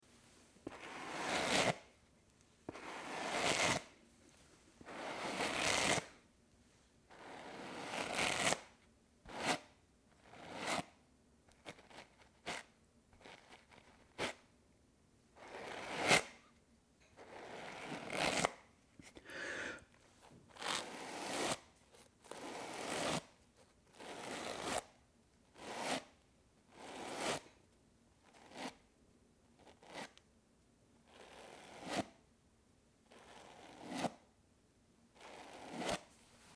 На этой странице вы найдете подборку звуков расчесывания волос – от мягких, едва слышных движений до четких, ритмичных проводок гребнем.
Звук расчесывания очень длинных волос девушкой